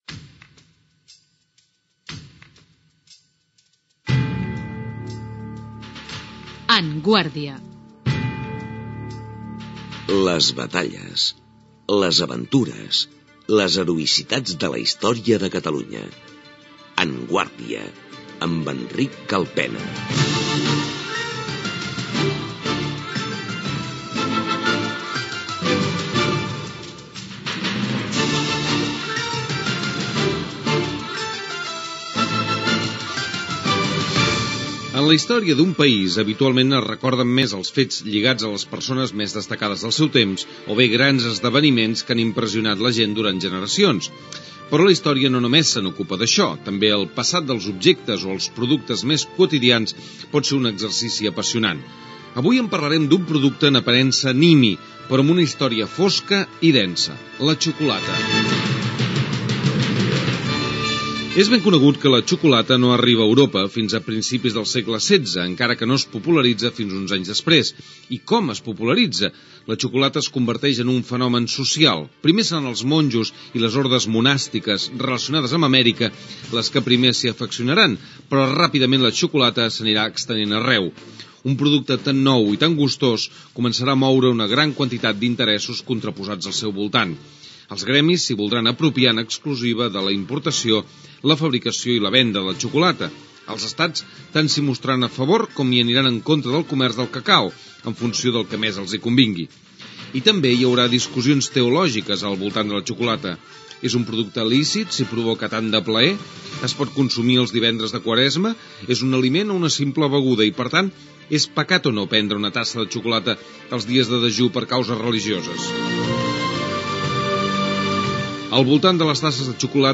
Careta del programa, la història de l'arribada de la xocalata a Europa, indicatiu del programa, reflexió sobre la xocolata de l'historiador Oriol Junqueras
Divulgació